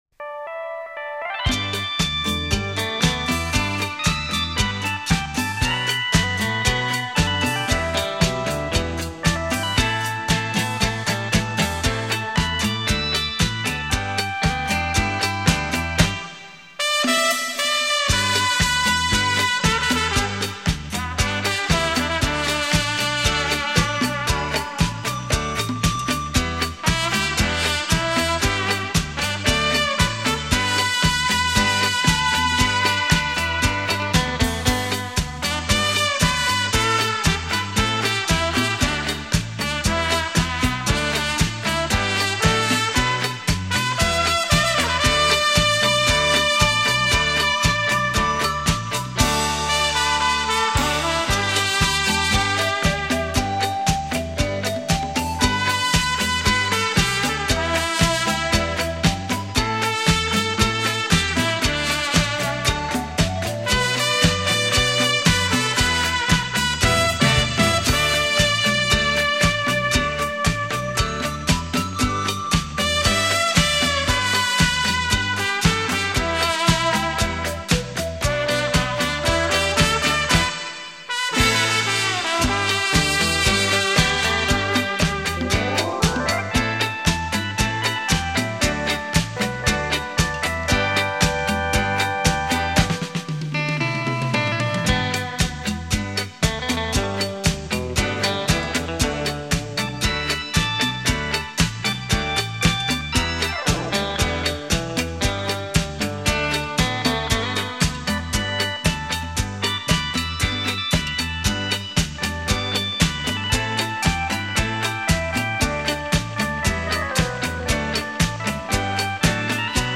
怀念的旋律 动人的音符 每一首都是抒情之选